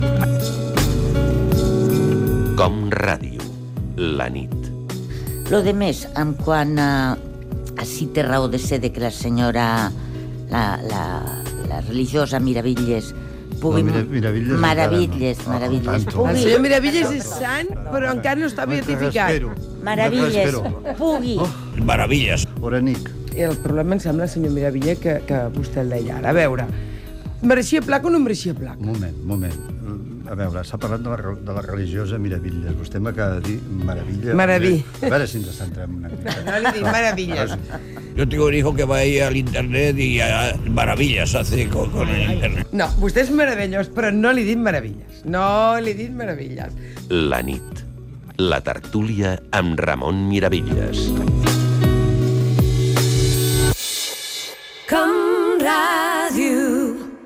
Entreteniment
Fragment extret de l'arxiu sonor de COM Ràdio.